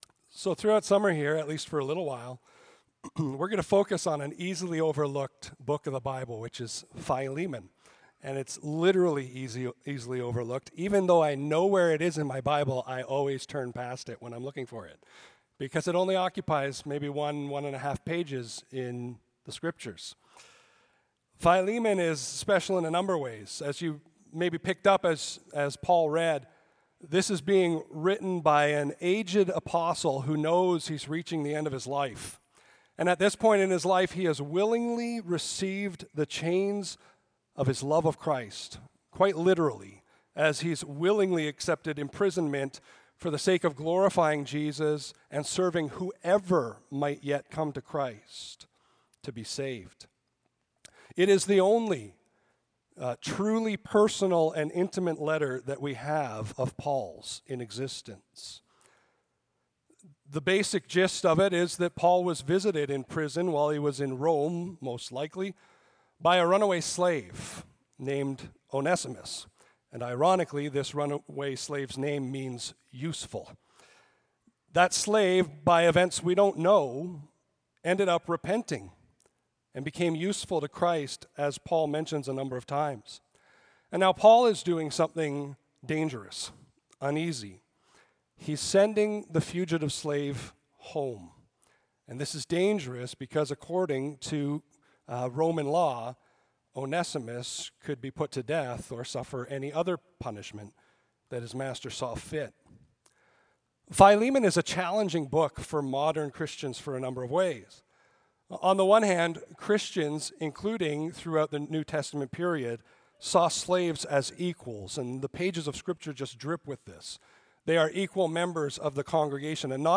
Audio Sermons - Clive Baptist Church